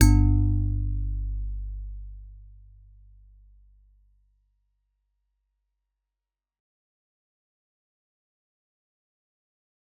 G_Musicbox-G1-f.wav